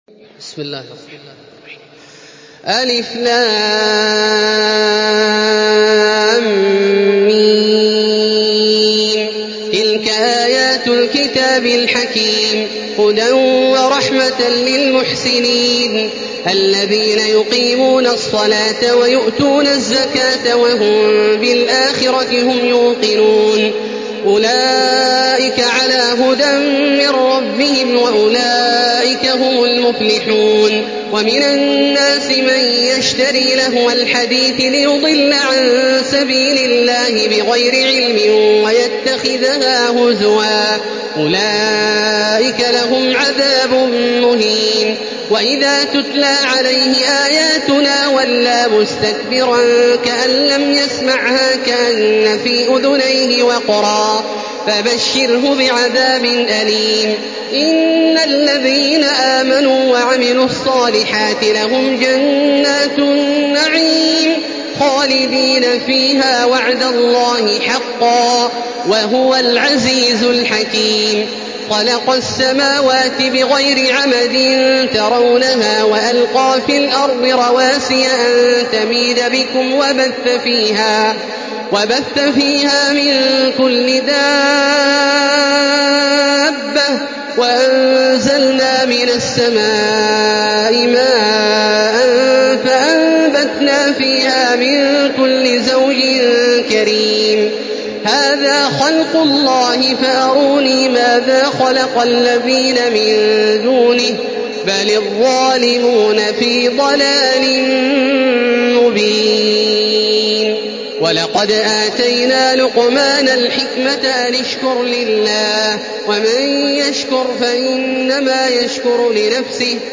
تحميل سورة لقمان بصوت تراويح الحرم المكي 1435
مرتل